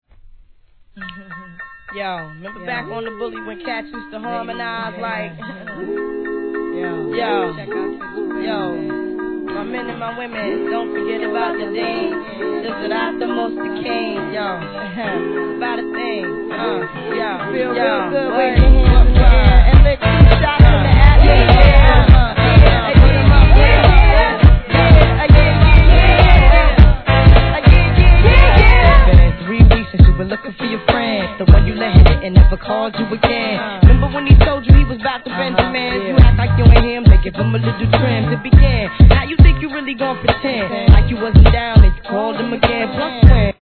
HIP HOP/R&B
彼女のヴォーカル、RAPとそのスキルの高さを再認識させたクラシック!!